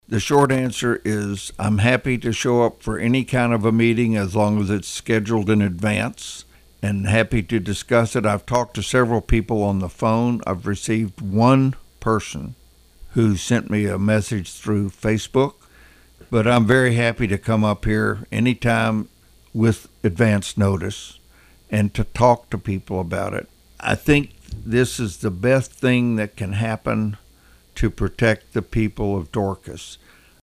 With audio from Rep. Maney